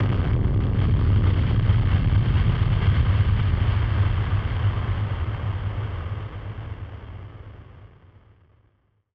BF_DrumBombD-01.wav